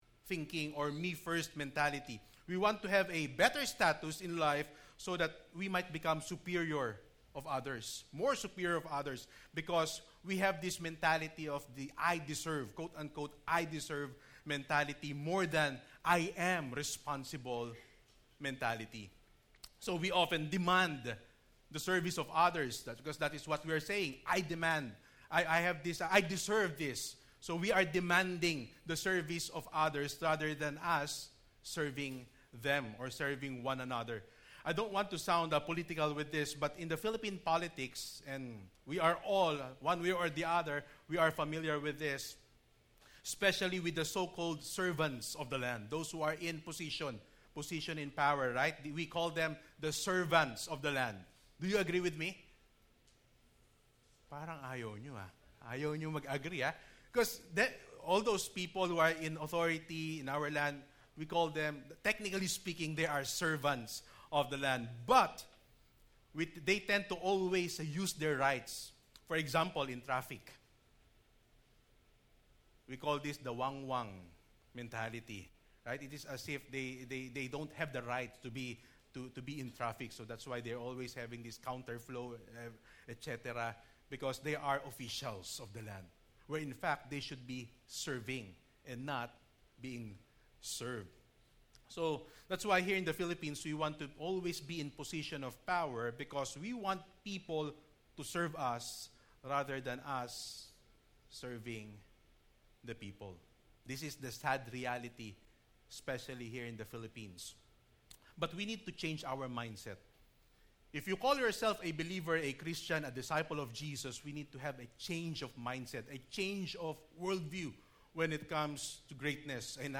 1st-Service-Preaching-Oct-8.mp3